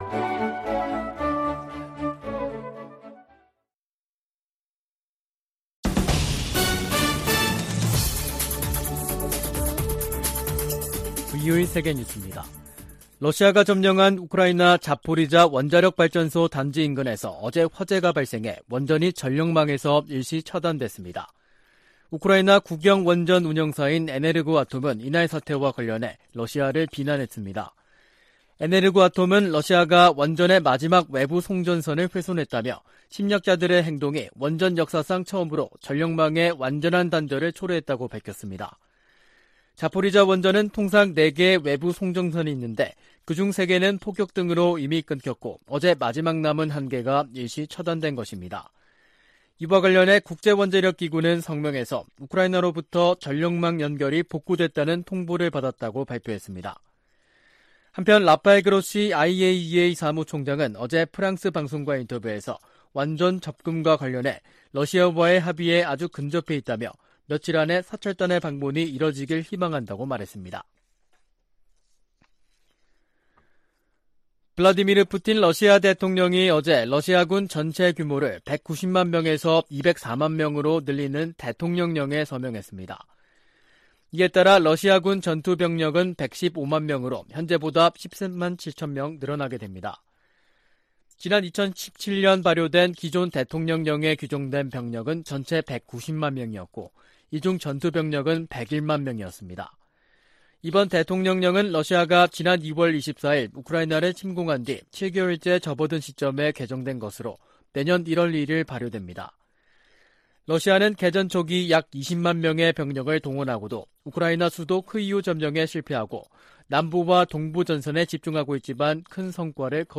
VOA 한국어 간판 뉴스 프로그램 '뉴스 투데이', 2022년 8월 26일 2부 방송입니다. 미 국무부 차관보와 한국 외교부 차관보가 서울에서 회담하고 북한의 도발 중단과 대화 복귀를 위한 공조를 강화하기로 했습니다. 미 국무부는 반복되는 러시아와 중국 폭격기의 한국 방공식별구역 진입을 역내 안보에 대한 도전으로 규정했습니다. 미국과 한국 정부가 중국 내 탈북 난민 상황을 거듭 우려하며 중국 정부에 난민 보호에 관한 국제의무 이행을 촉구했습니다.